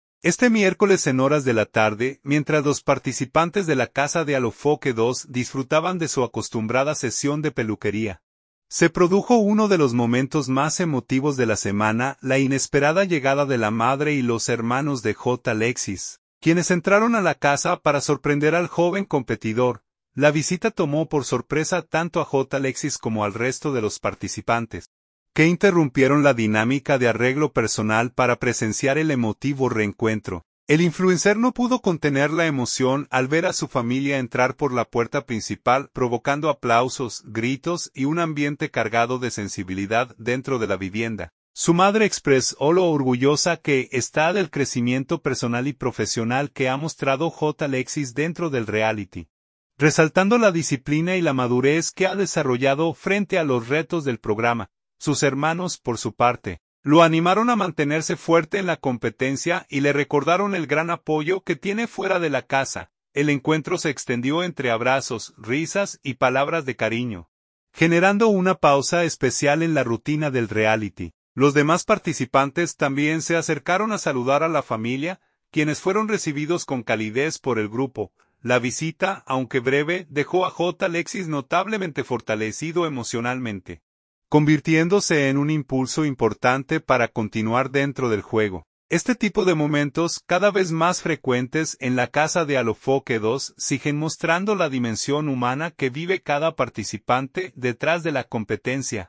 El encuentro se extendió entre abrazos, risas y palabras de cariño, generando una pausa especial en la rutina del reality.